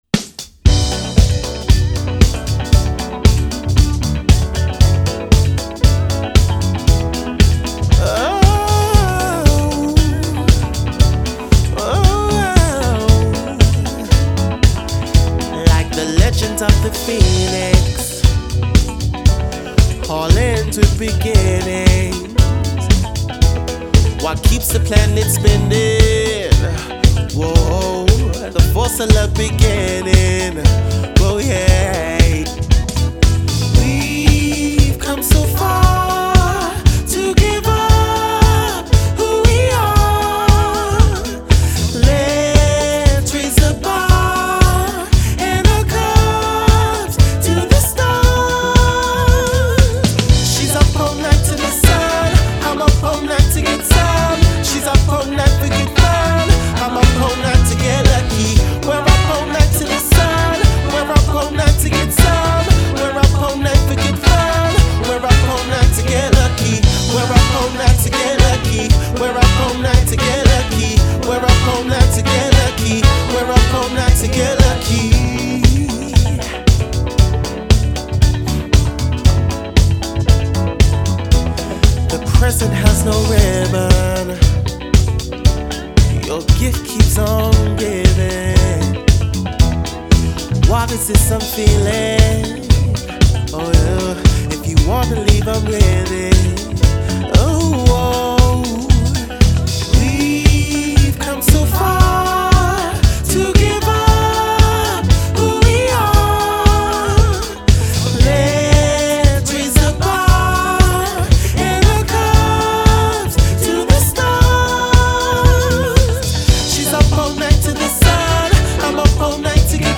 captivating male and female vocals
From £4,690 + travel | Wedding / Party Band